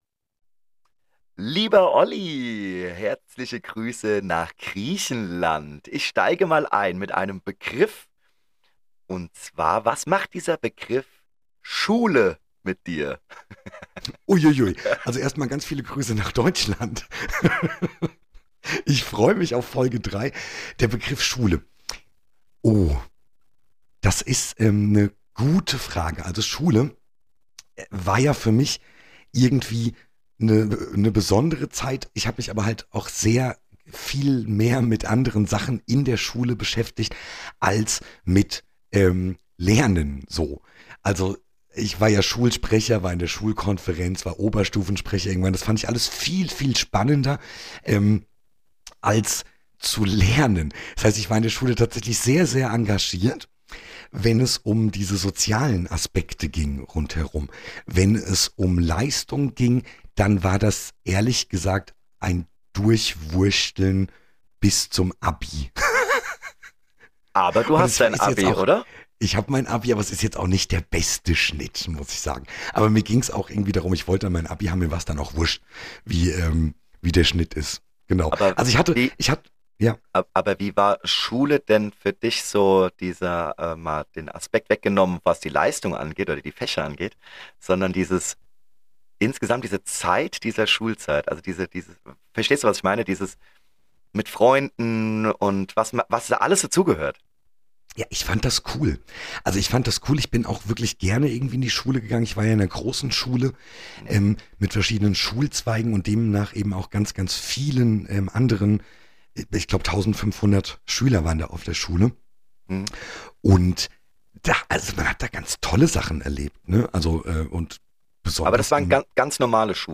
Beschreibung vor 1 Jahr Ach wie aufregend - ca. 2500 km voneinander getrennt und doch eine Podcast-Aufnahme hinbekommen.